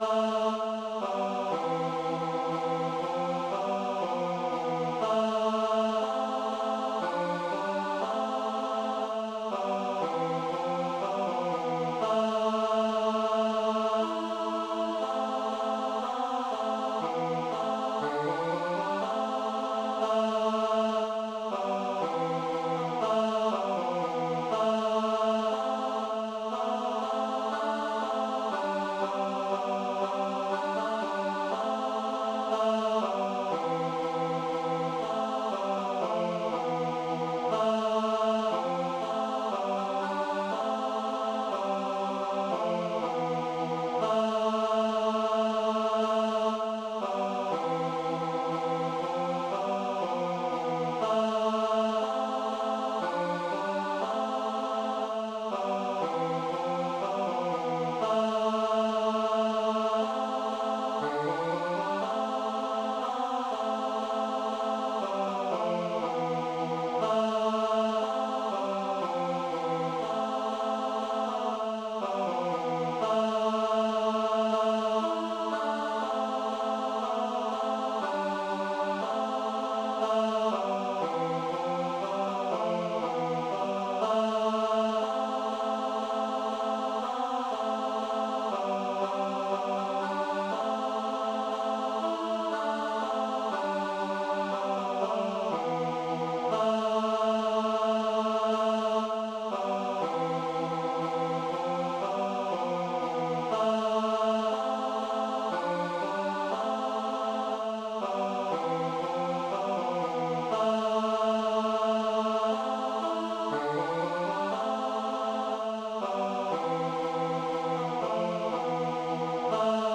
Воскресные тропари по непорочных для двух голосов